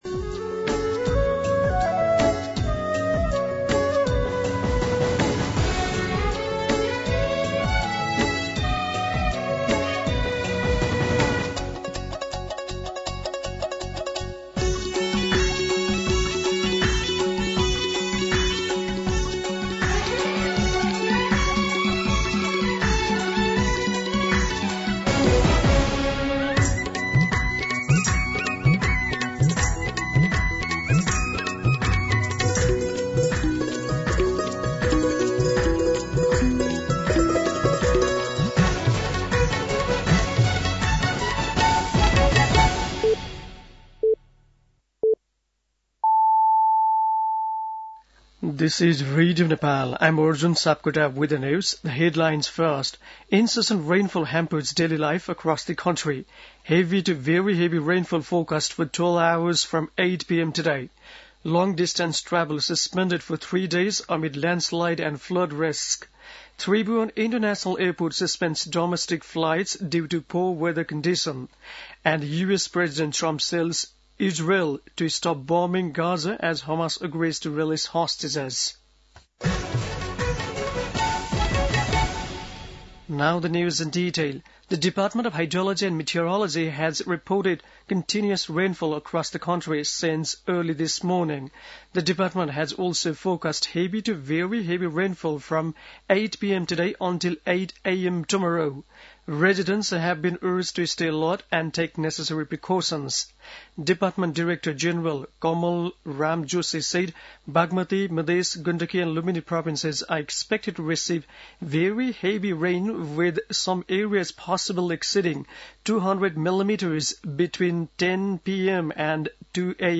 दिउँसो २ बजेको अङ्ग्रेजी समाचार : १८ असोज , २०८२
2pm-English-News.mp3